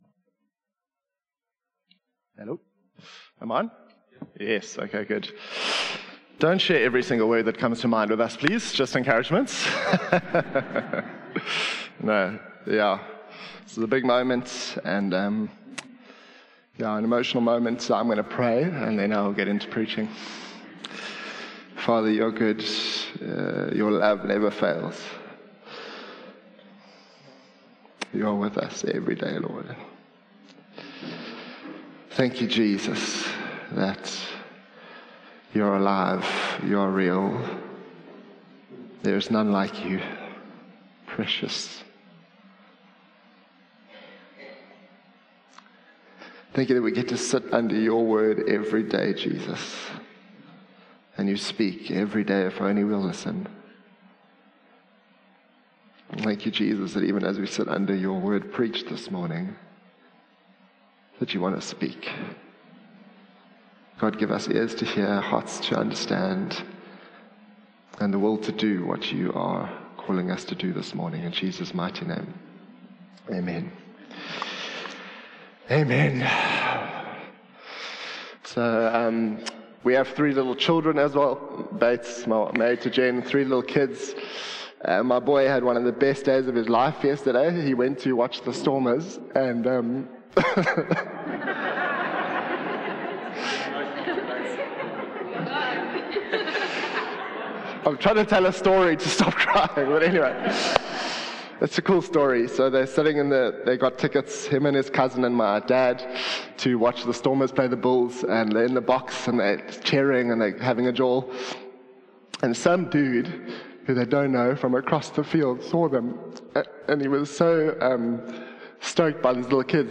One-Hope-Sermon-9-February-2025.mp3